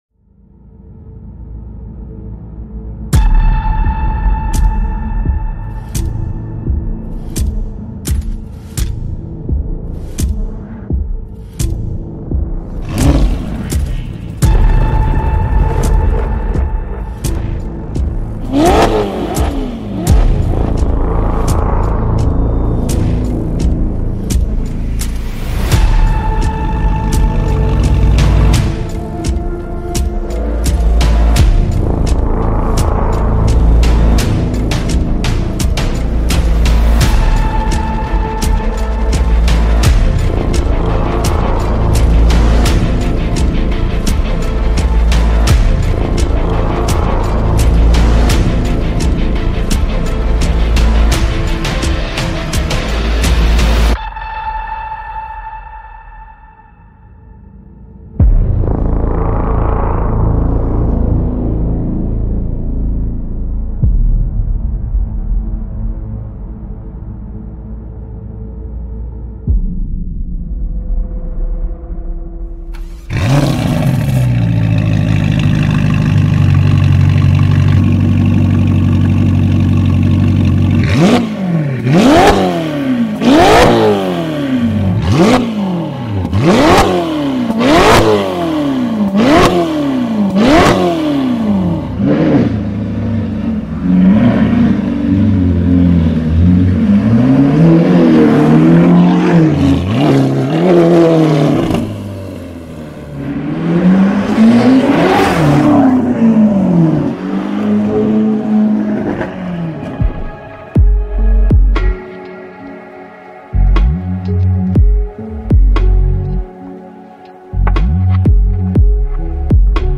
2022 Ferrari Roma by MANSORY sound effects free download